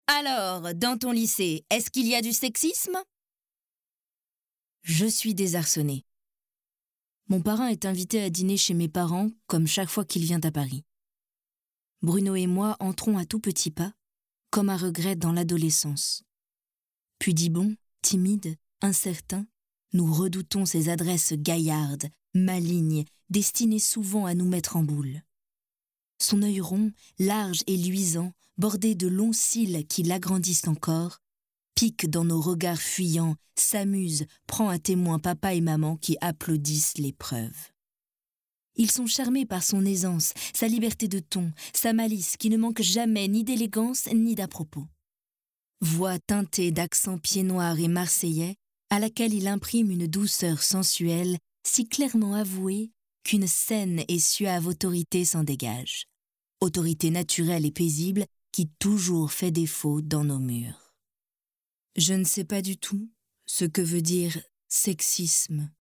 Livre audio
Voix 25 - 38 ans - Soprano